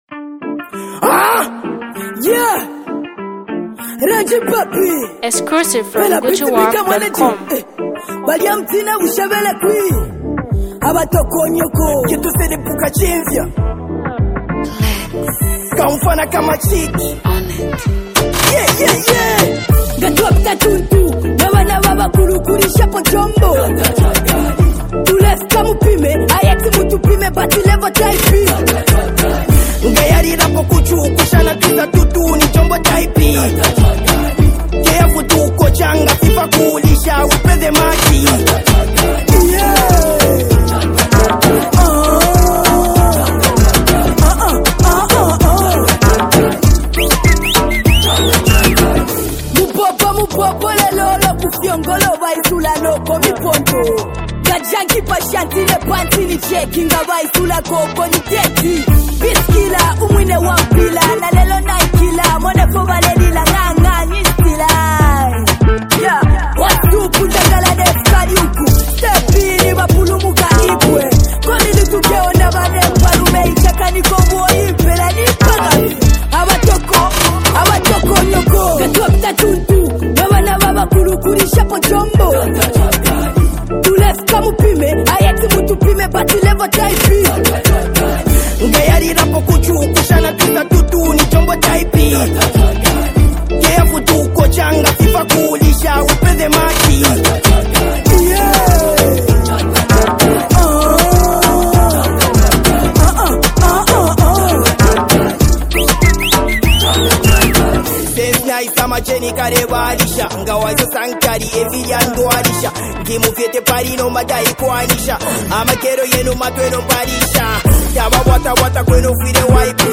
Kopala Music Excellence
a new Kopala hit